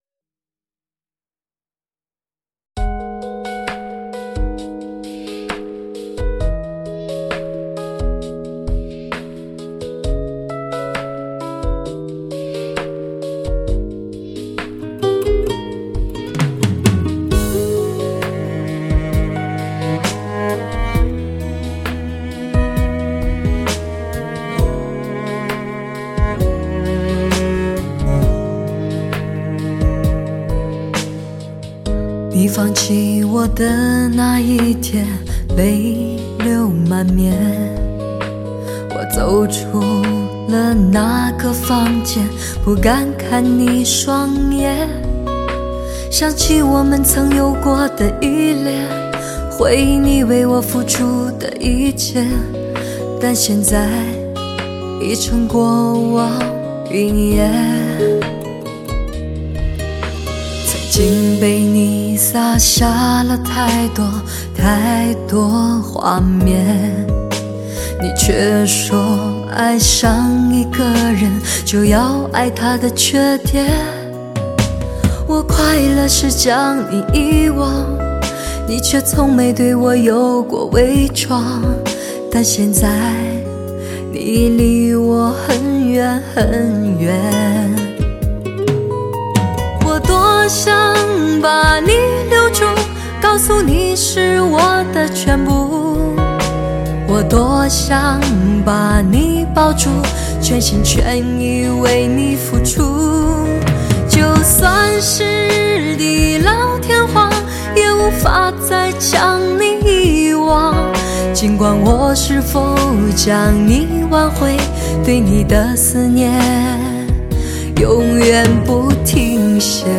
发烧热曲 环绕靓声
炙手可热的流行发烧热曲
极具声色感染力的3D环绕靓声
德国版HD高密度24bit数码录音
倍具声色感染力的时尚人声，超高临场感的聆音效果，让音乐在音响重播系统中发挥出无限的声色潜能，尤显人声的结像凸浮。